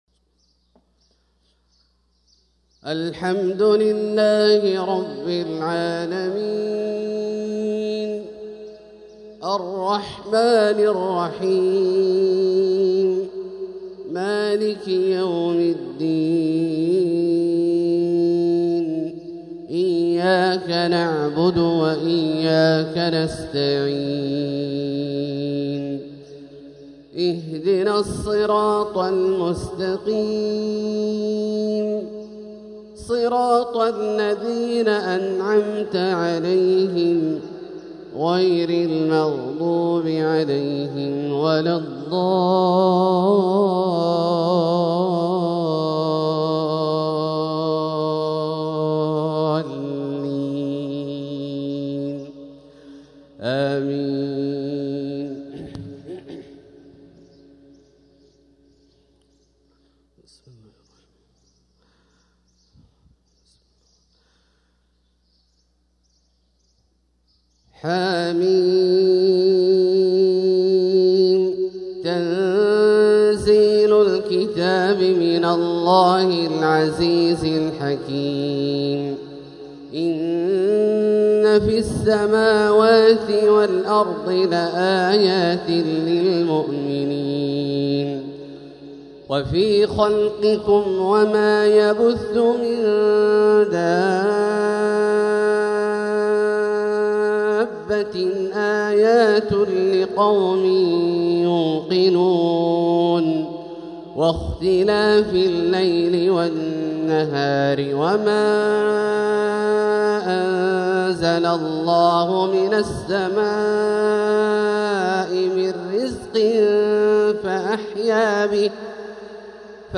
ترتيل بديع لفواتح سورة الجاثية | فجر الاثنين 6-7-1446هـ > ١٤٤٦ هـ > الفروض - تلاوات عبدالله الجهني